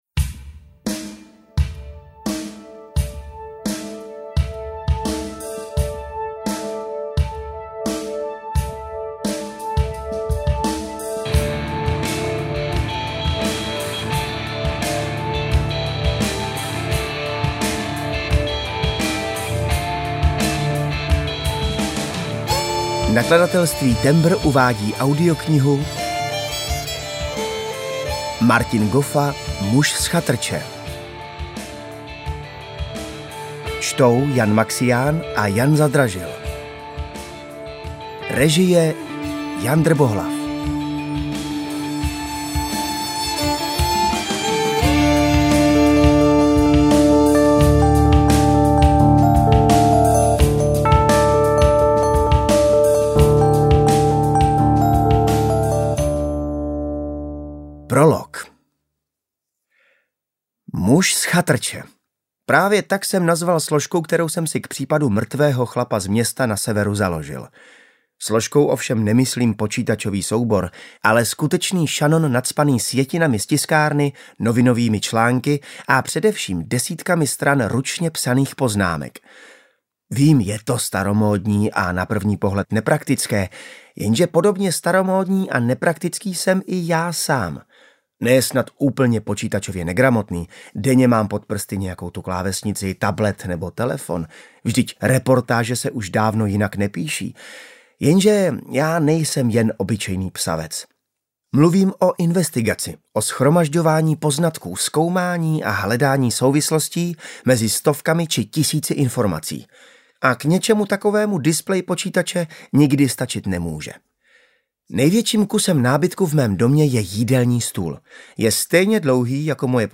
UKÁZKA Z KNIHY
audiokniha_muz_z_chatrce_ukazka.mp3